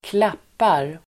Uttal: [²kl'ap:ar]